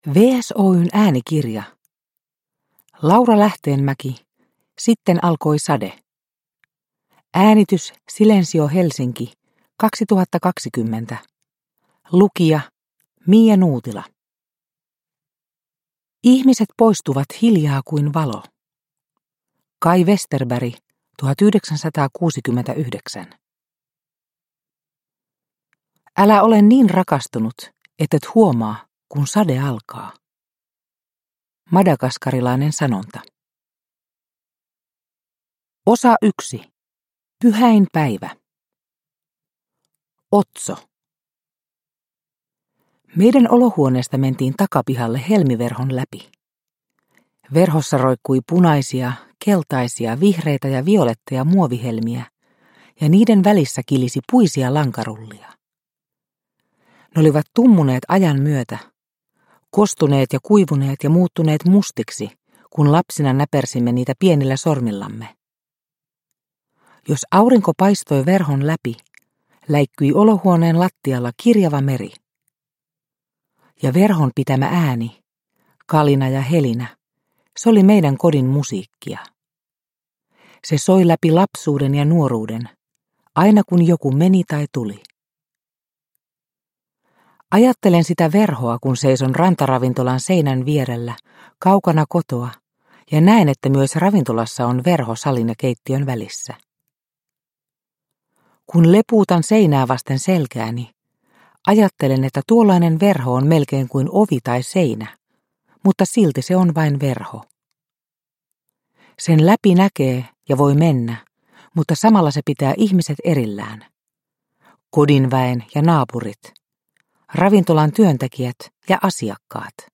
Sitten alkoi sade – Ljudbok – Laddas ner